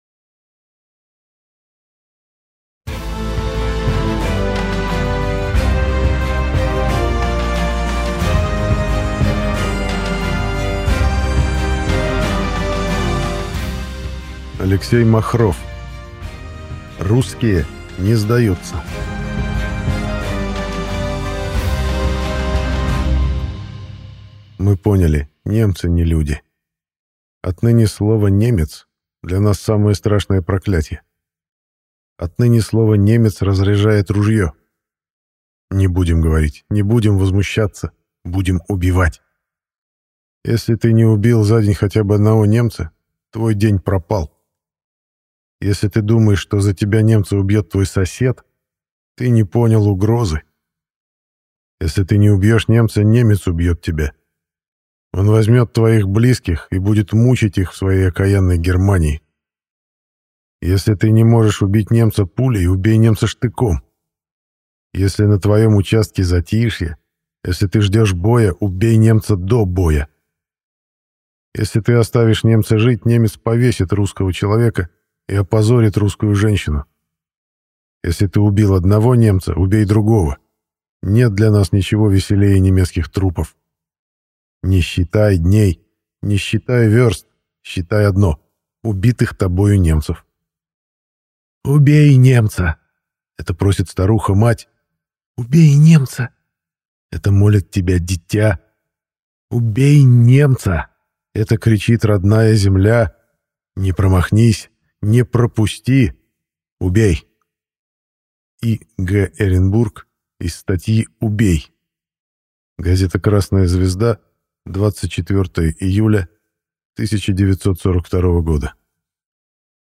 Аудиокнига Русские не сдаются!